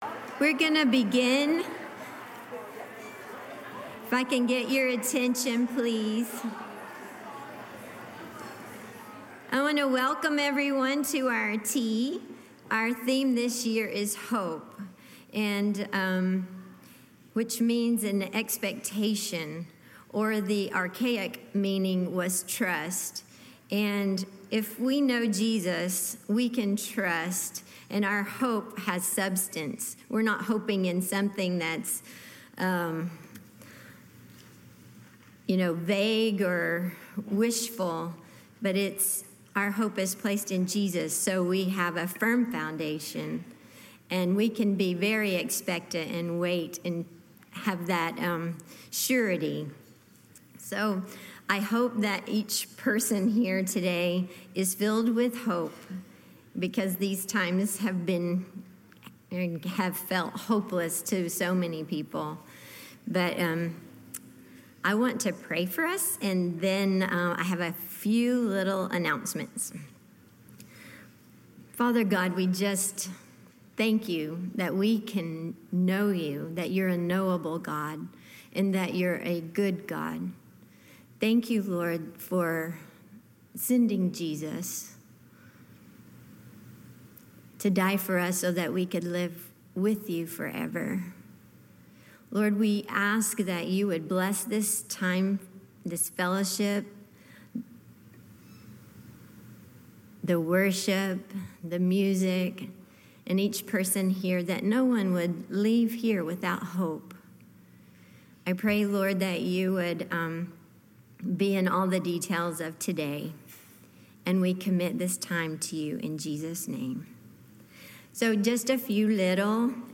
2021 Resolve Women's Christmas Tea